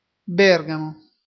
Bergamo (Italian: [ˈbɛrɡamo]